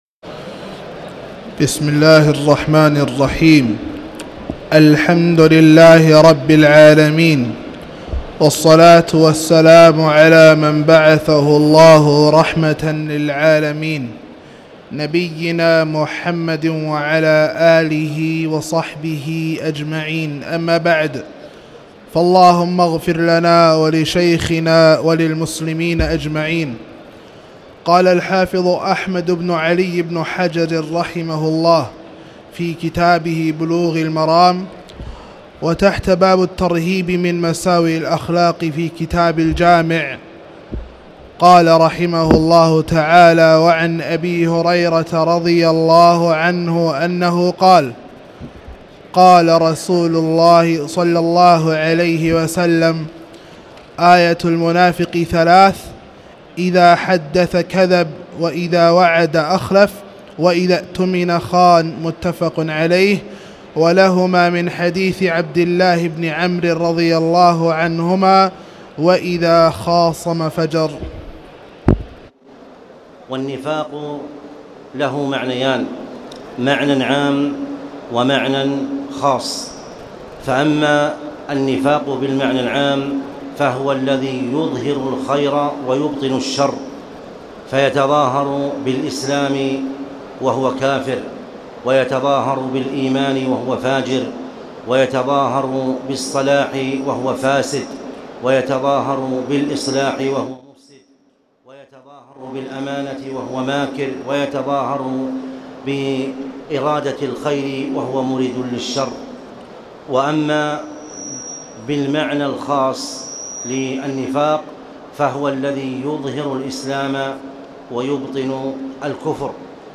تاريخ النشر ٢٥ رمضان ١٤٣٨ هـ المكان: المسجد الحرام الشيخ